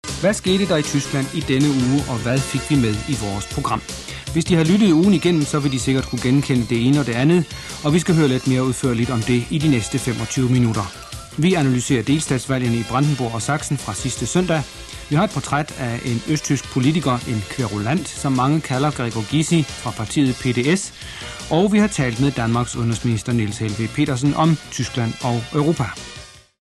deutscher Profi-Sprecher.
Sprechprobe: Industrie (Muttersprache):